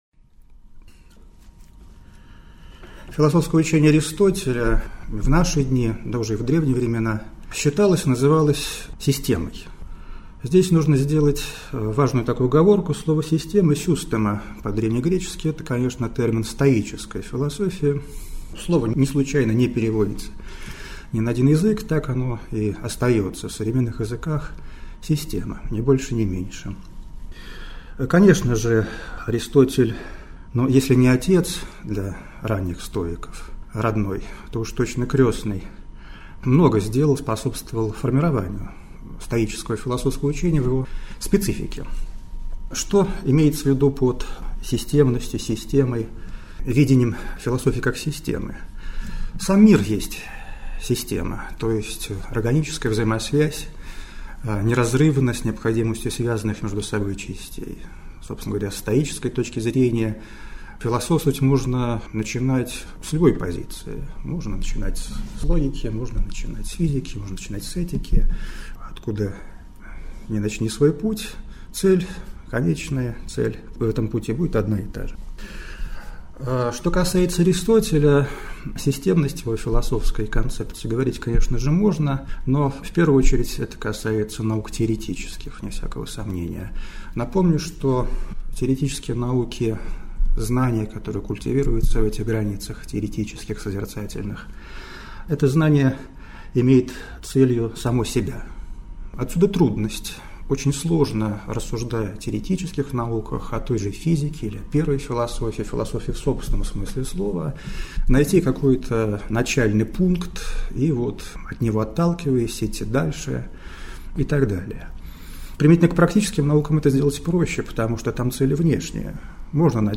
Аудиокнига «Метафизика». Учение о категориях. Понятие «сущности» | Библиотека аудиокниг